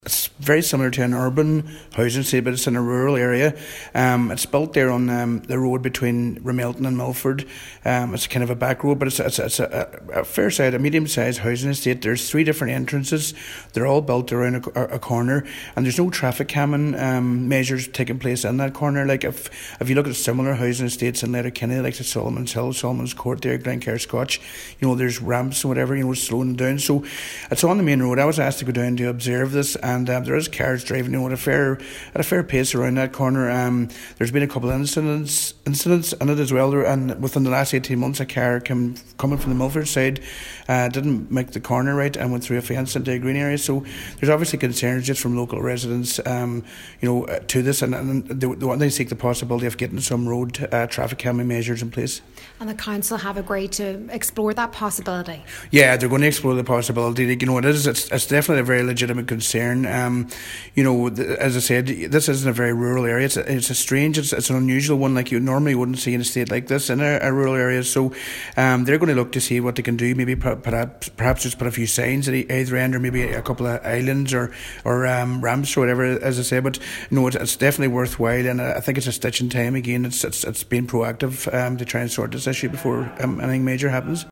Cllr Adrian Glackin says a few minor incidents have already happened in the area: